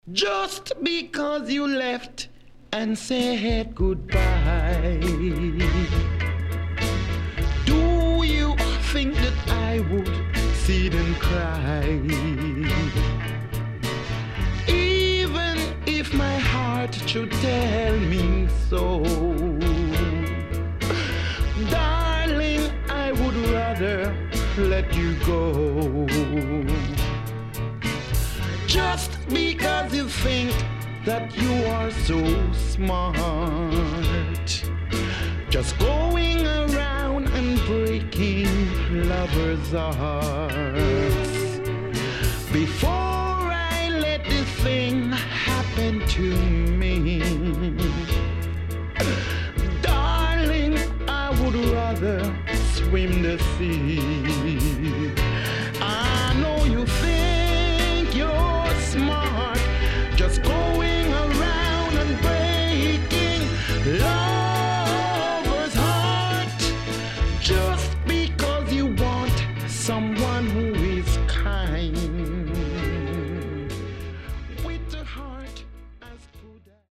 SIDE A:少しノイズ入ります。